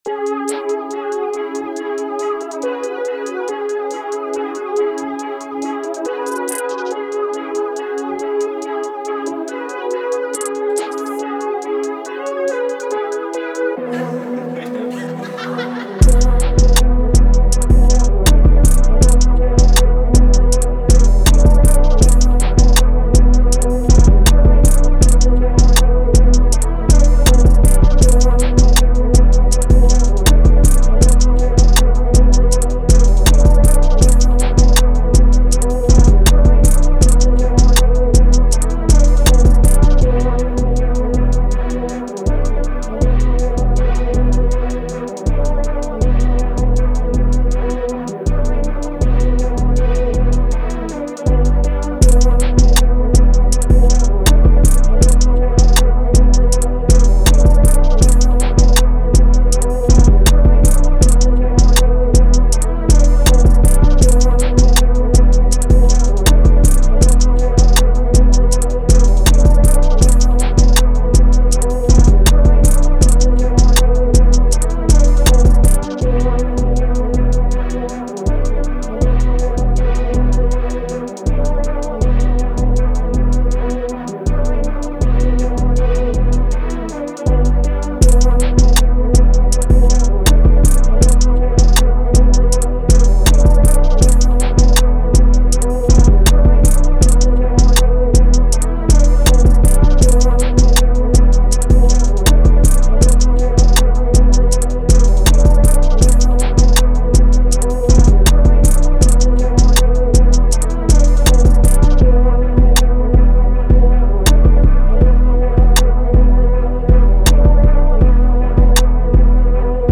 Hip Hop
Em
Street themed, hustler music
Braggadious